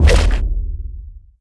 healthpickup.wav